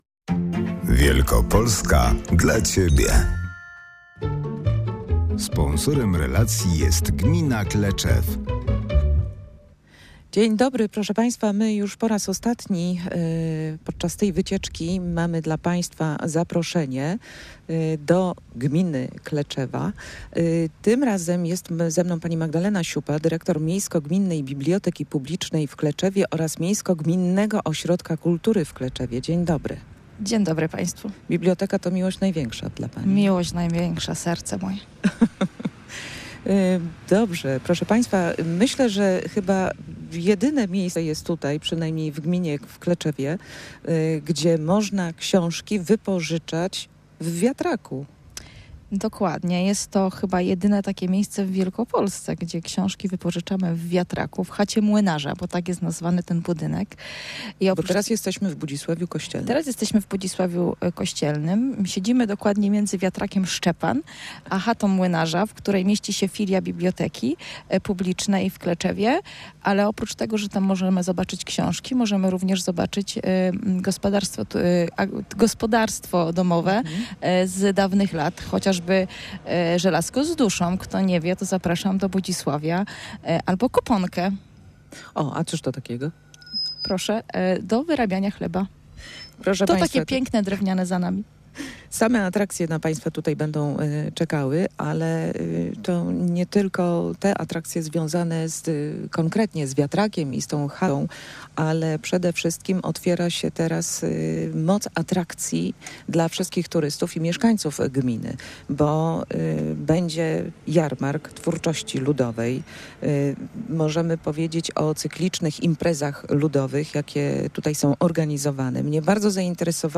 Dziś zawitaliśmy do gminy Kleczew we wschodniej Wielkopolsce.